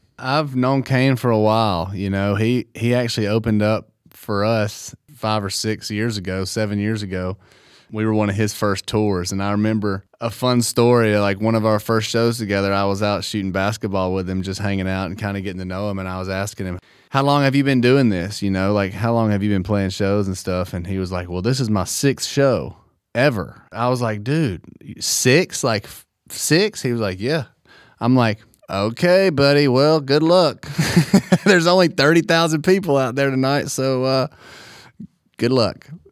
Tyler Hubbard talks about Kane Brown opening up for Florida Georgia Line when he was first starting out.